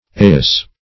Eyas \Ey"as\, a.